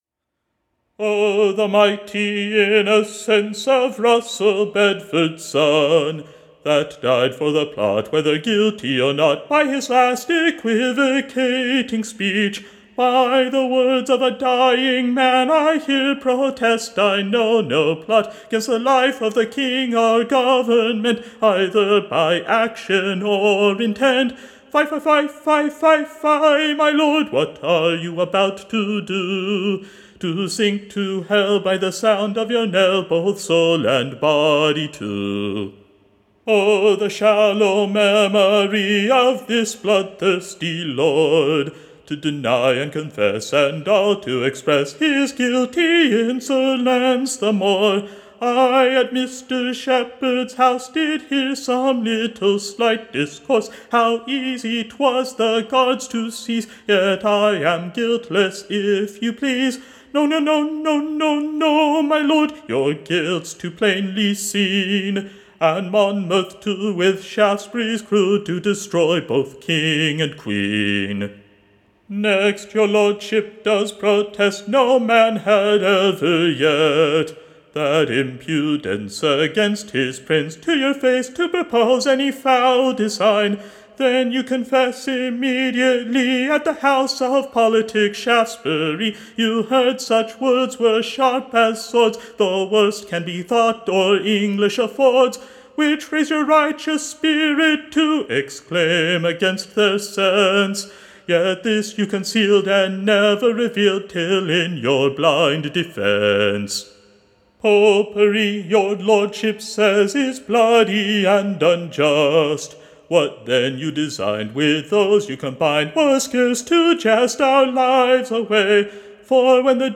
Recording Information Ballad Title RUSSEL's Farewel.